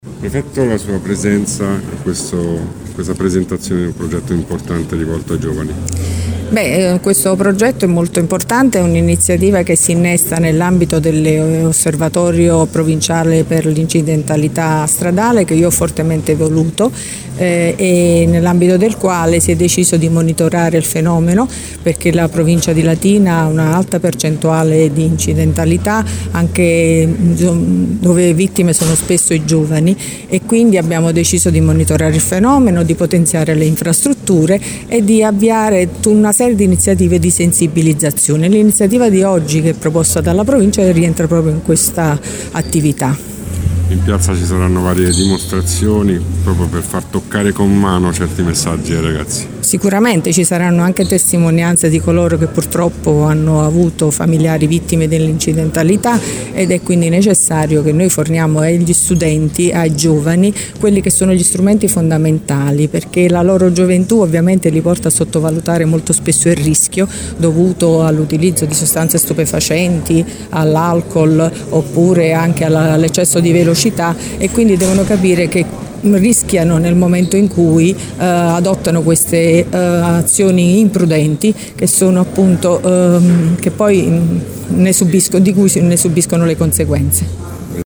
LATINA – E’ in corso al Teatro D’Annunzio di Latina  il primo incontro dedicato alla sicurezza stradale voluto dalla Provincia di Latina, in collaborazione con la Prefettura e la Questura, per sensibilizzare i giovani sul tema e portare avanti le attività dell’Osservatorio sulla sicurezza stradale.
Le attività dell’Osservatorio stradale sono state rilanciate sin dal suo insediamento dalla prefetta di Latina Vittoria Ciaramella che oggi partecipa alla giornata e ha voluto lanciare il suo messaggio.